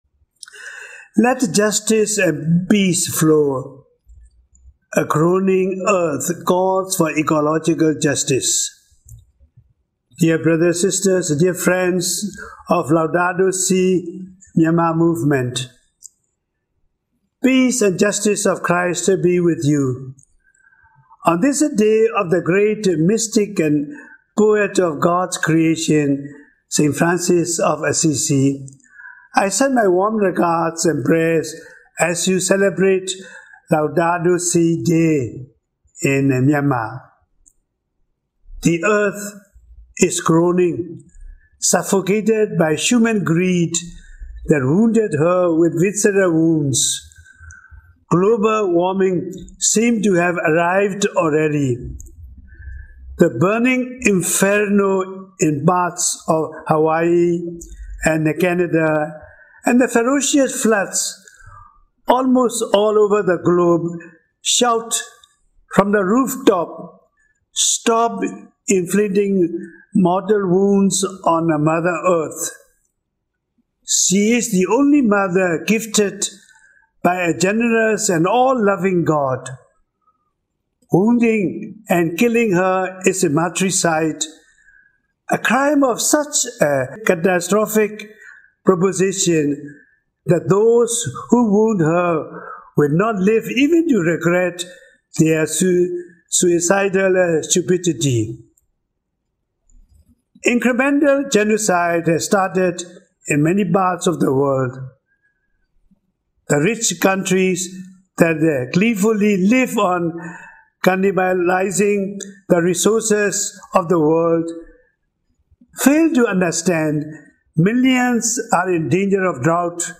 The World Day of Prayer for the Care of Creation Speech by His Eminence Cardinal Charles Bo